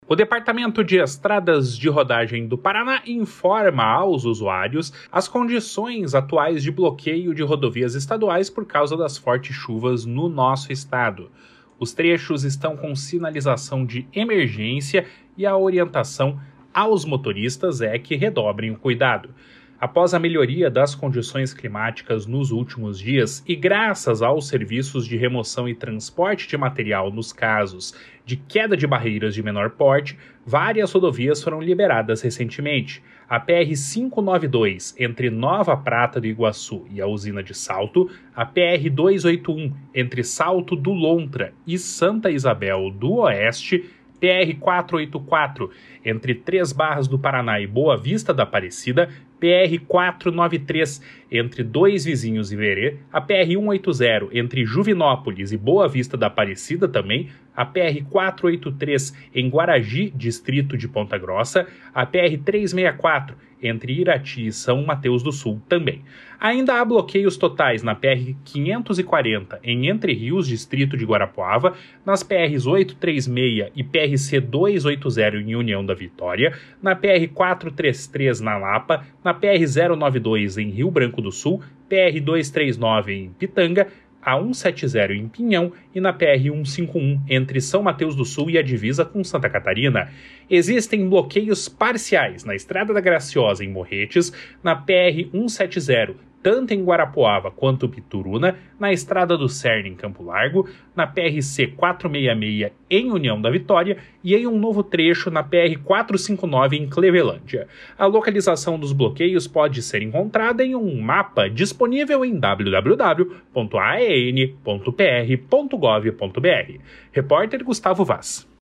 Balanço das rodovias.mp3